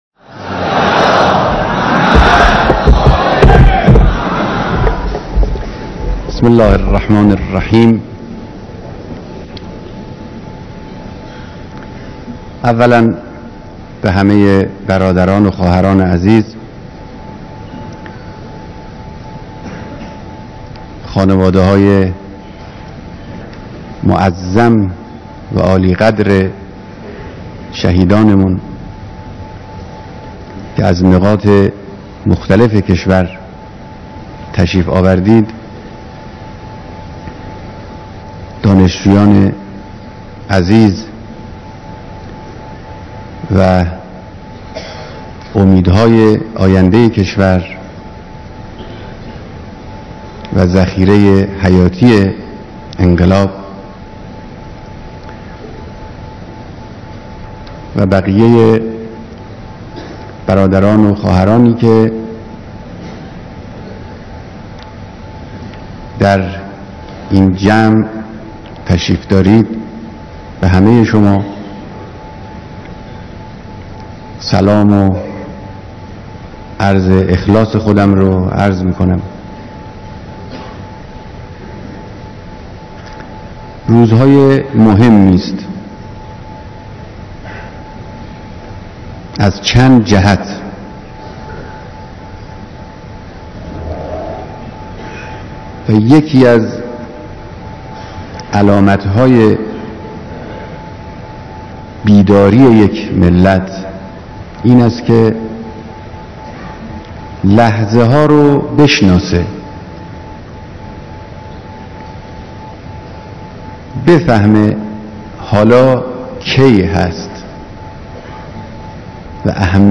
بیانات رهبر انقلاب در دیدار اقشار مختلف مردم